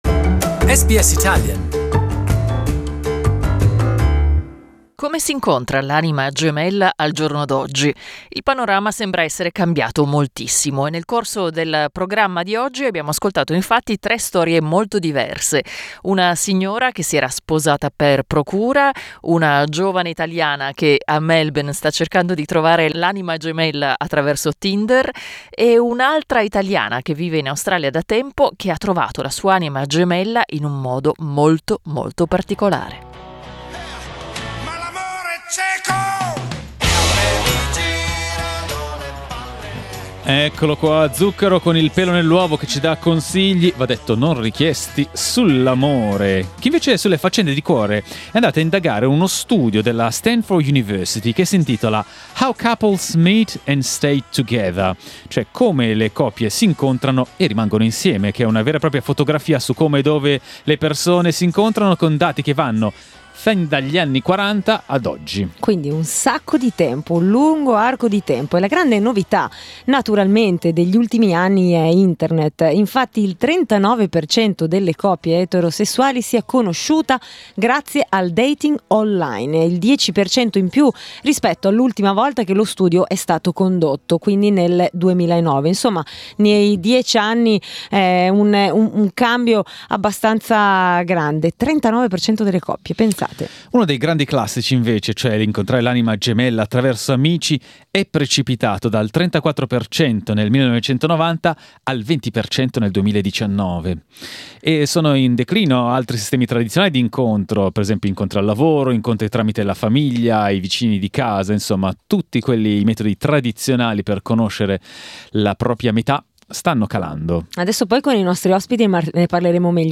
Dating has changed and online apps and websites are now very important. We asked three women from three different generations about their dating experiences.